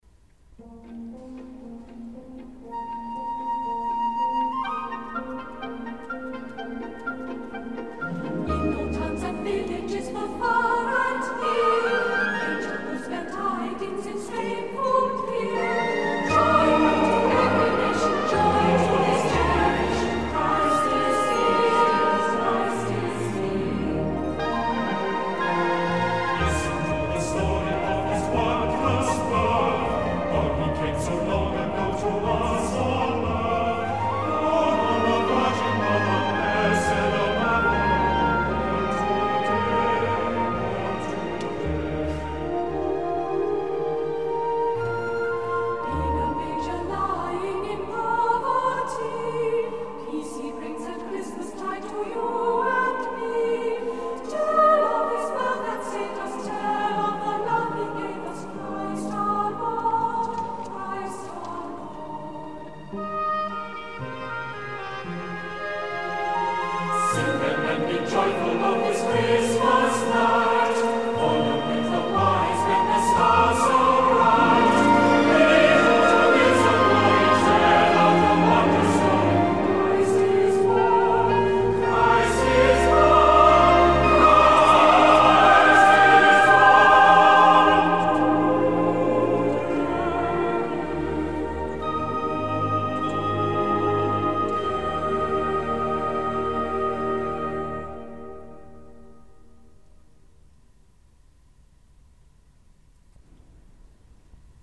Genre:XMAS CLASSICAL VOCAL